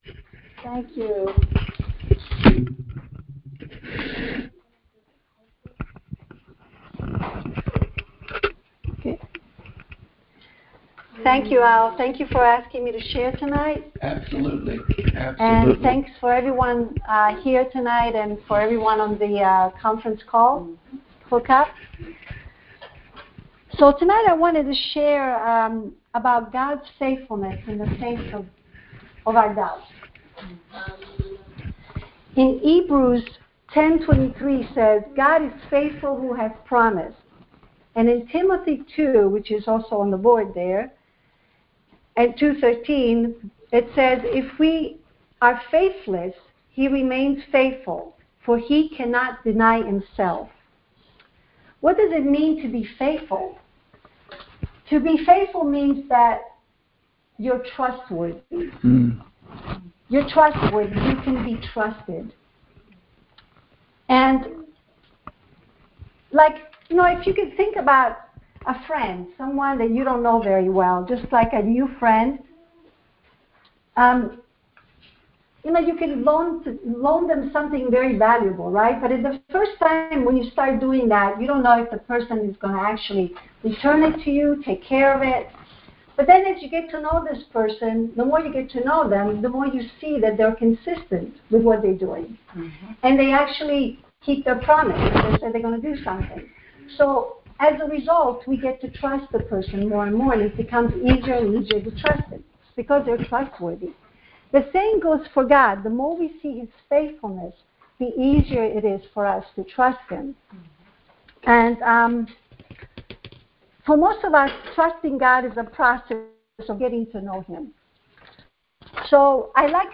Details Series: Conference Call Fellowship Date: Monday, 05 May 2025 Hits: 292 Scripture: 1 Thessalonians 5:24 Play the sermon Download Audio ( 9.93 MB )